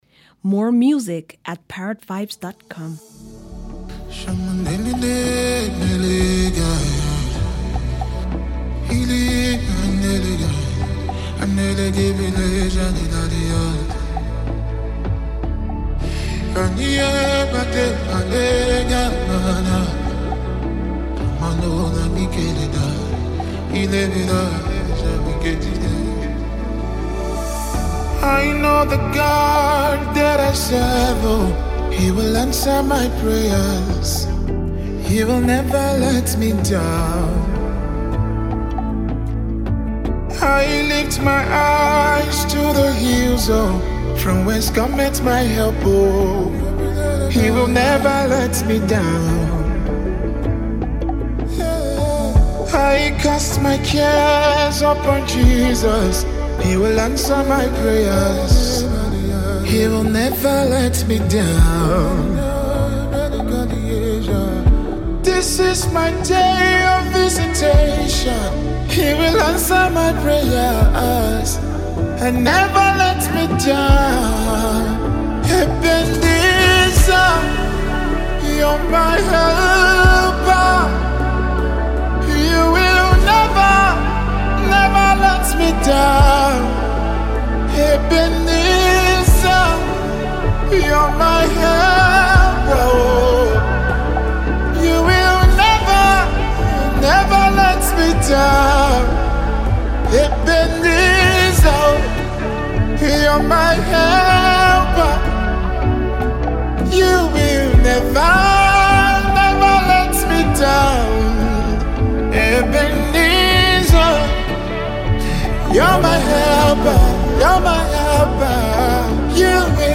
Extraordinarily skilled Nigerian gospel recording artist
soul-stirring song